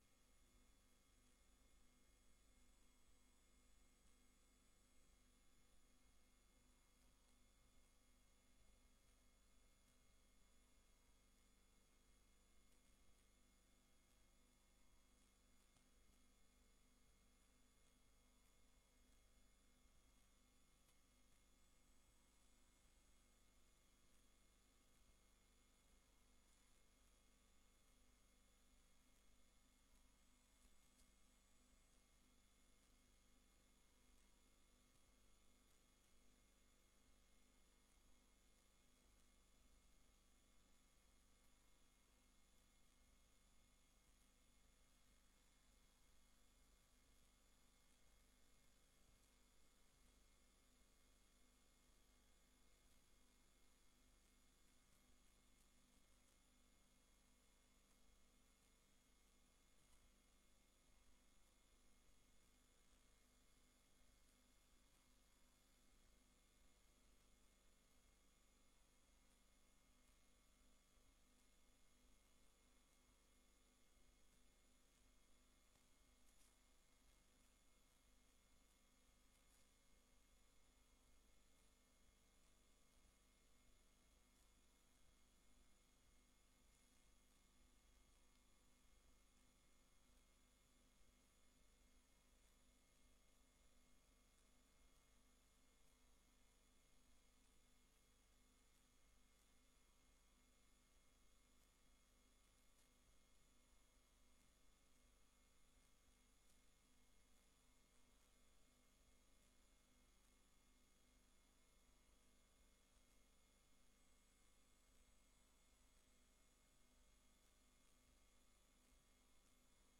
Raadsvergadering Papendrecht 08 juli 2024 17:00:00, Gemeente Papendrecht